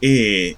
48 ɪ vowel near-close near-front unrounded [
near-close_near-front_unrounded_vowel.wav